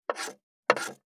561魚切る,肉切りナイフ,まな板の上,
効果音